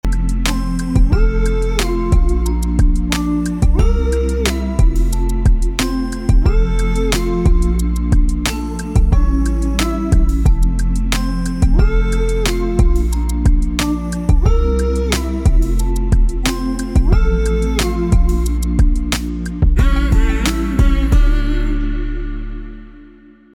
• Качество: 192, Stereo
мужской голос
мелодичные
Electronic
спокойные
beats
Легкая мелодия